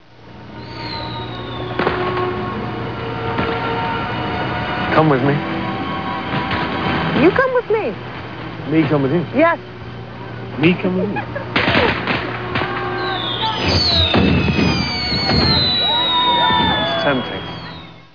All sounds are of Paul McGann from the telefilm, Doctor Who.
Sounds were originally sampled at 22 kHz, 16-bit mono with GoldWave, then resampled to 11 kHz, 8-bit mono to reduce their file size.